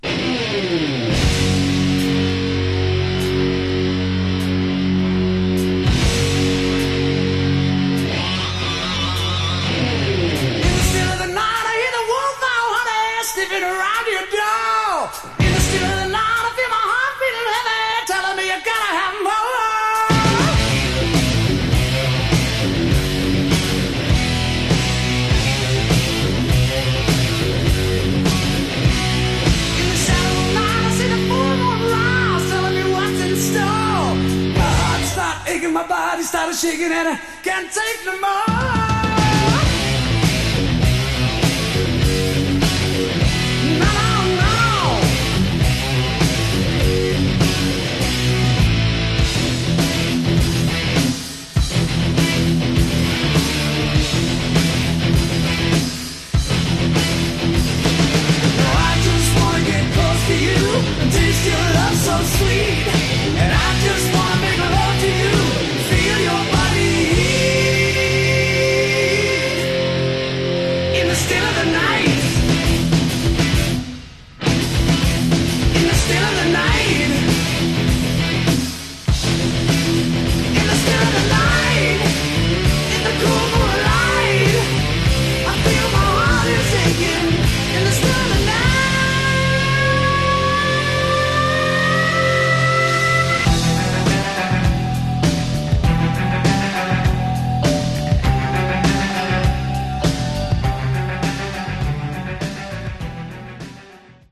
Genre: Hard Rock/Metal